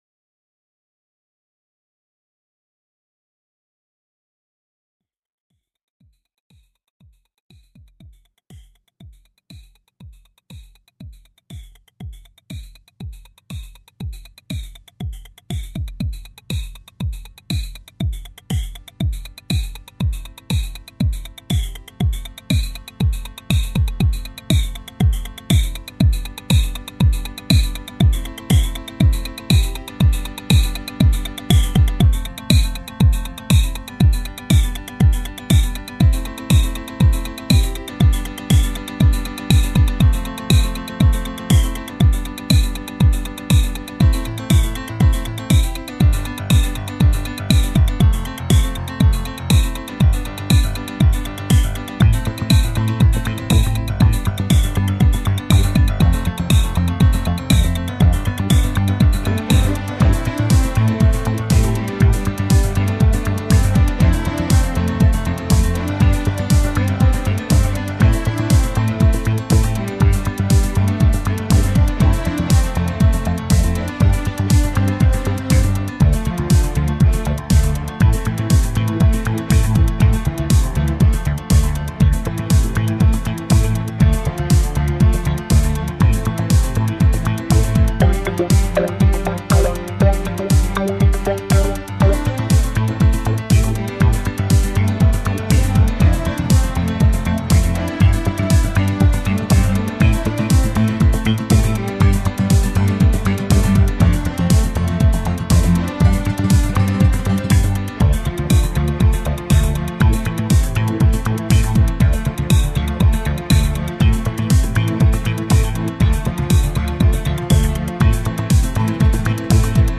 dance/electronic
Ambient
Electro
New age